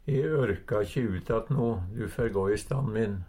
i stan min - Numedalsmål (en-US)